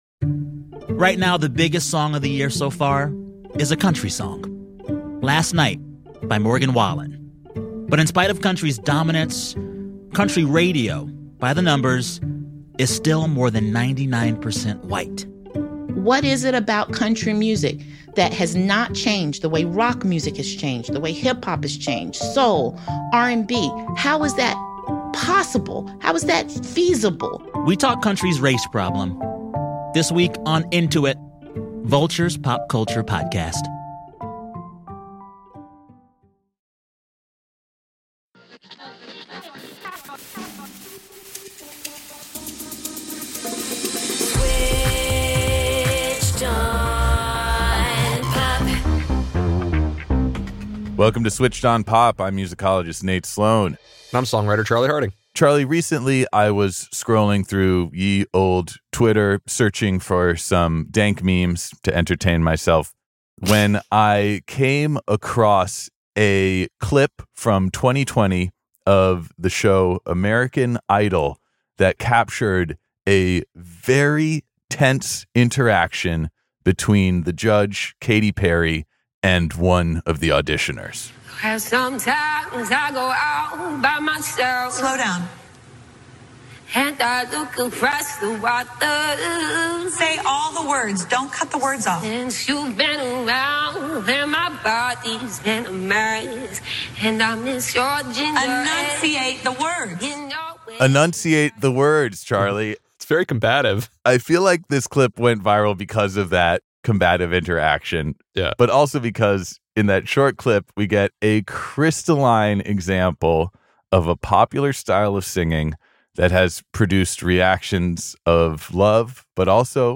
In this episode we speak to vocal coaches and journalists to to ask: Where did this cursive style come from?